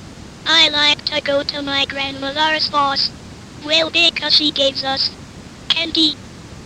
I actually use an audio except from their NETtalk demonstration to show how the machine learning algorithm changes from prattling nonsense to correctly reading the English text in a training set.
At this point it is easy to point out that the machine-learning algorithm correctly pronounces words it has not seen before, meaning that the computer has “learned” a general solution to the very complex problem of reading English text.
nettalk.02.wav